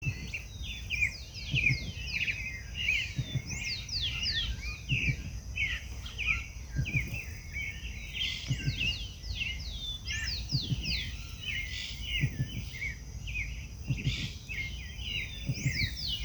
Ctenomys sp.
Localización detallada: Parque San Carlos
Condición: Silvestre
Certeza: Vocalización Grabada
Tuco-tuco_1.mp3